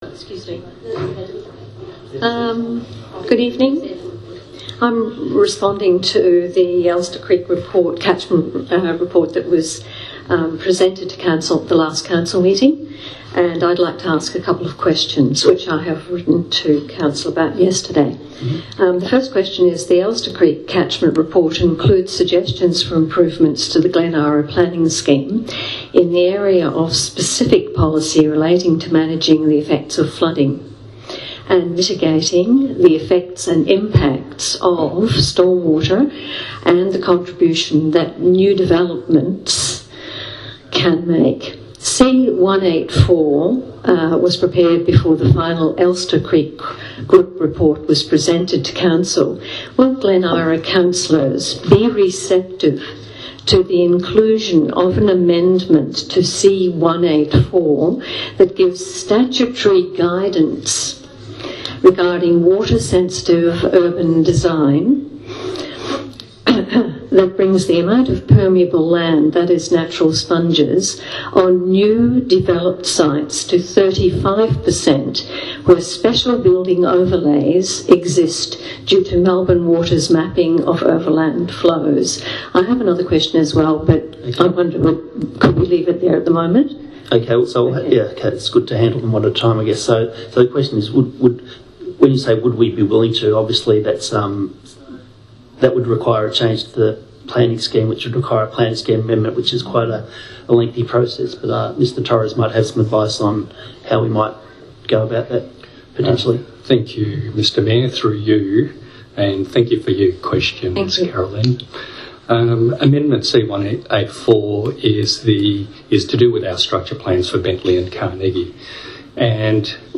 It features one question on flooding, Water Sensitive Urban Design, and permeability standards from a resident at the last council meeting, in the ‘participation’ phase of the meeting.